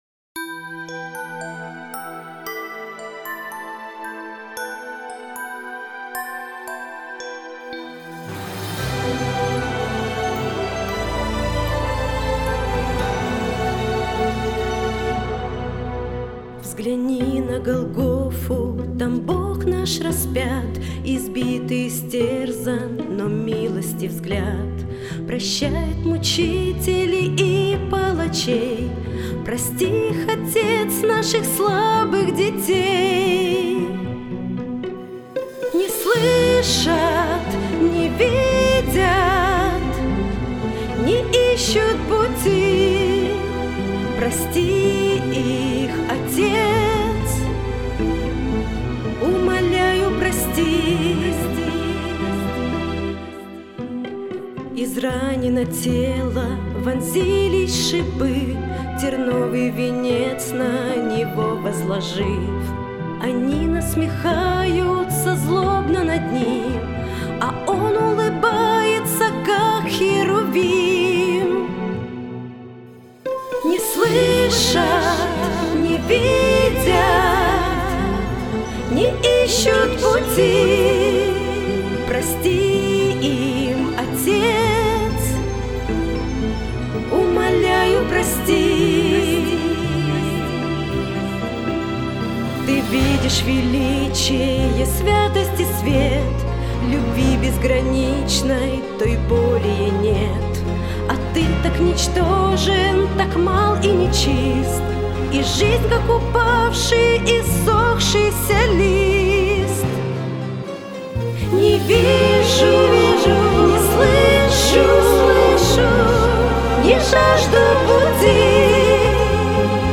песня
175 просмотров 213 прослушиваний 14 скачиваний BPM: 75